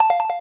correctNoApplause.ogg